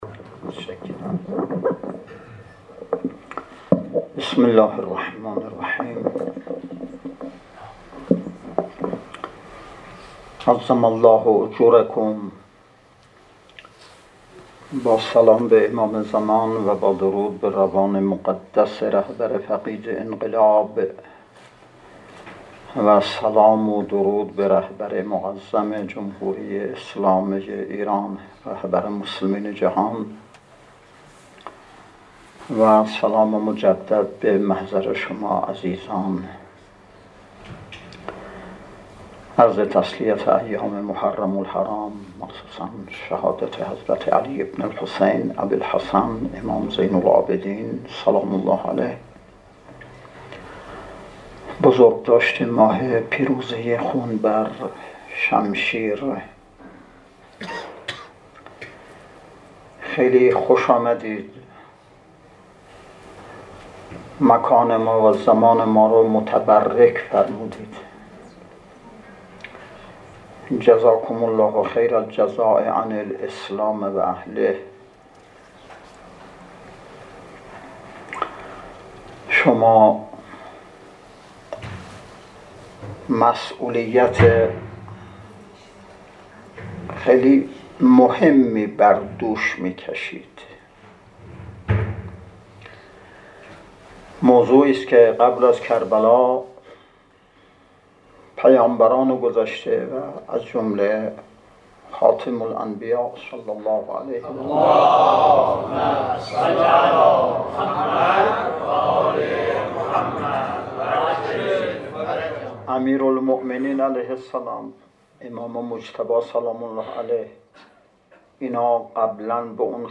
فایل صوتی سخنان نماینده ولی فقیه در خراسان جنوبی در دیدار با روسای هیئات مذهبی بیرجند در مورخه ۹۵/۰۸
فایل صوتی بیانات آیت الله عبادی در دیدار با روسای هیئات مذهبی بیرجند